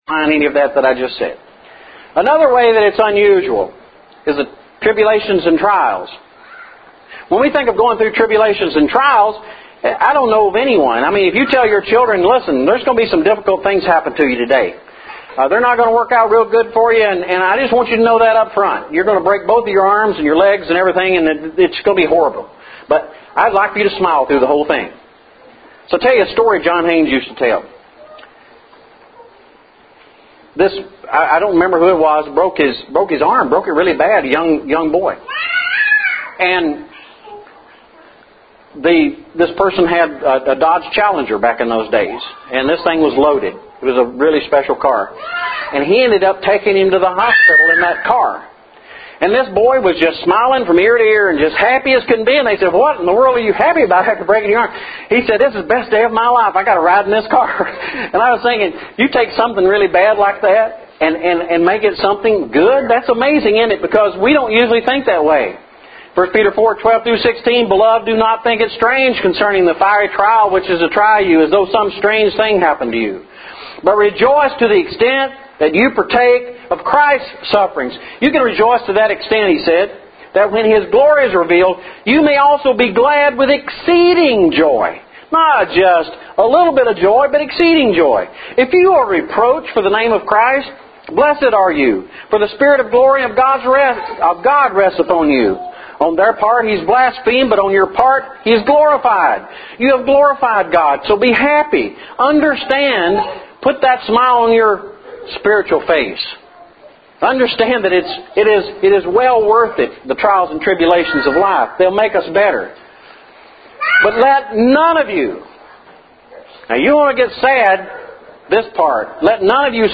Note that lesson 2 was started late.